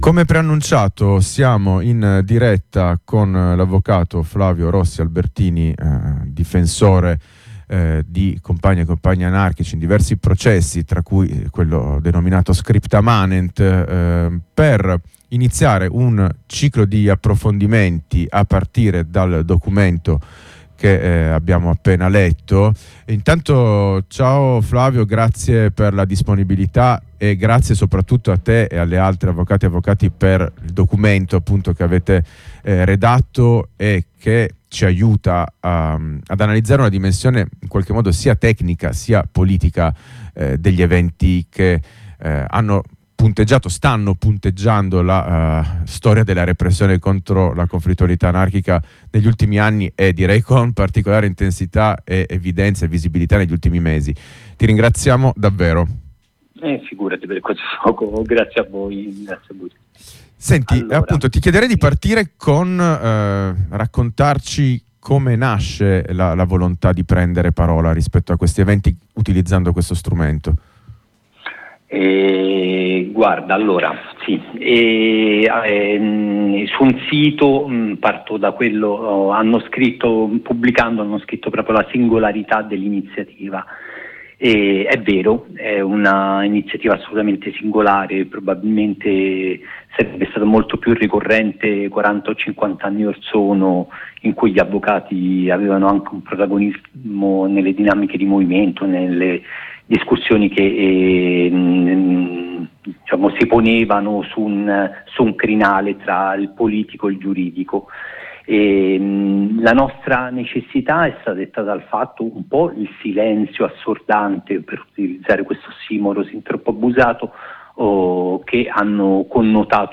Estratto dalla puntata del 17 ottobre 2022 di Bello Come Una Prigione Che Brucia